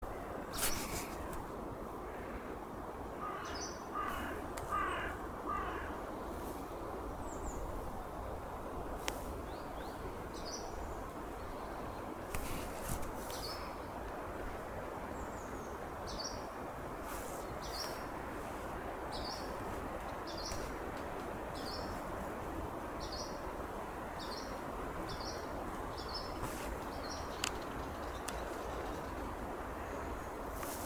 Putni -> Ķauķi -> 4
Hjūma ķauķītis, Phylloscopus humei